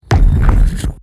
دانلود افکت صوتی شلیک گلوله